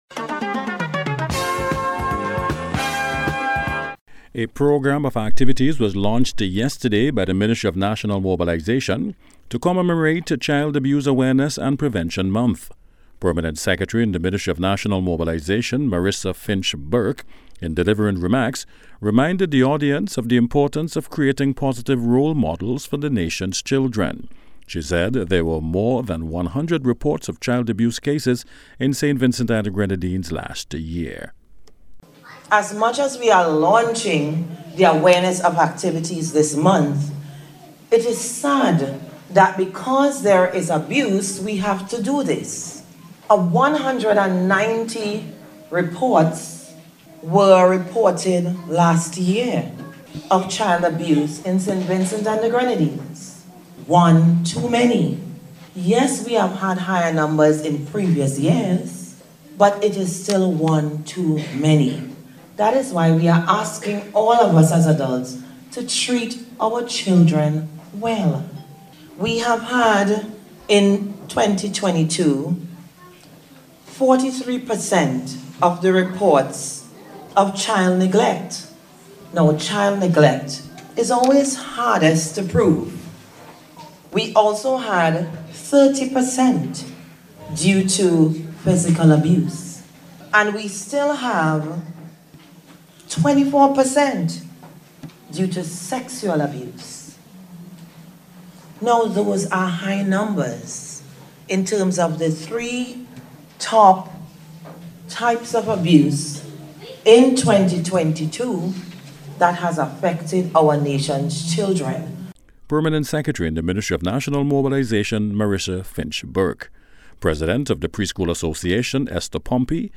NBC’s Special Report – Wednesday April 5th 2023